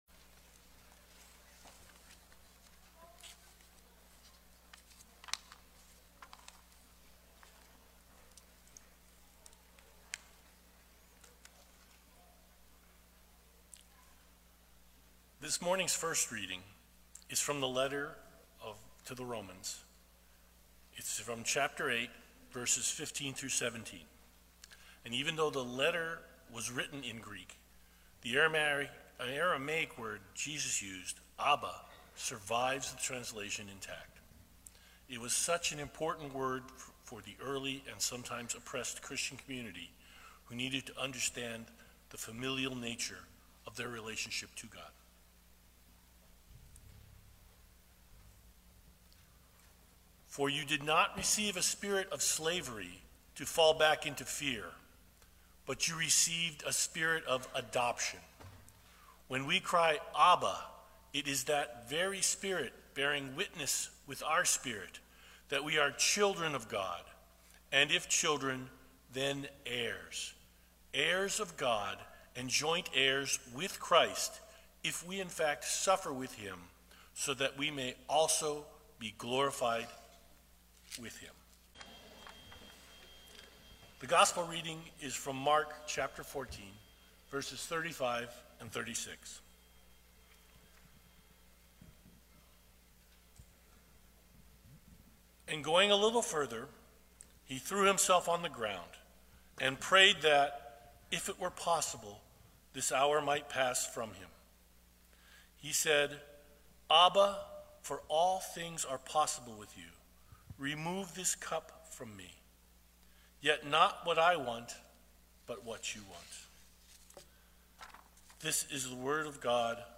Sermons | Faith United Methodist Church of Orland Park